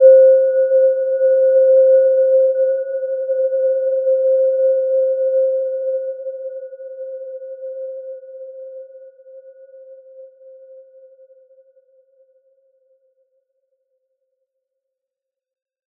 Gentle-Metallic-4-C5-p.wav